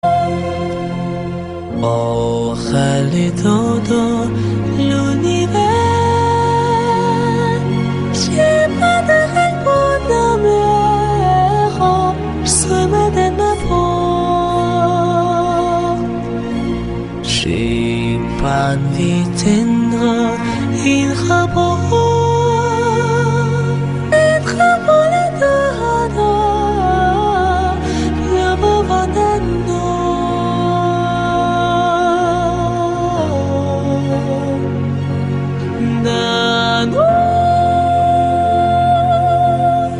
романтические
поп , спокойные